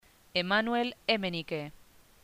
Emmanuel EMENIKEEmánuel Émenike